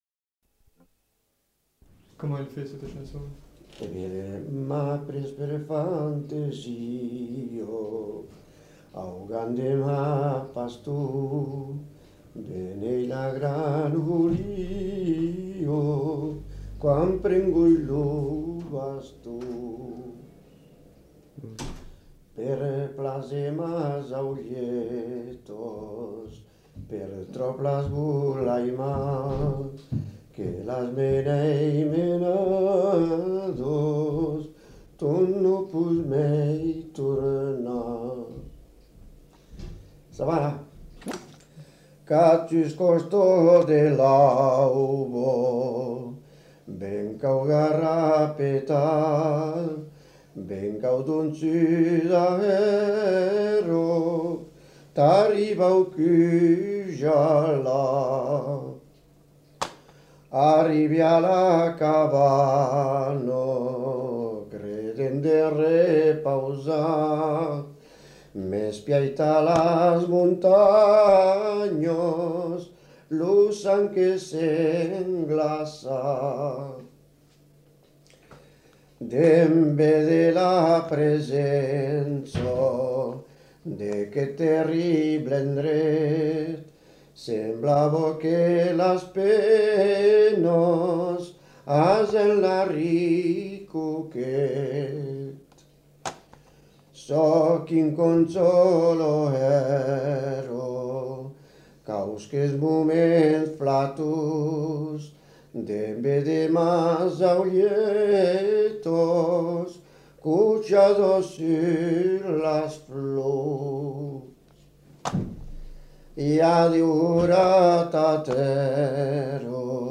Aire culturelle : Béarn
Lieu : Bielle
Genre : chant
Effectif : 1
Type de voix : voix d'homme
Production du son : chanté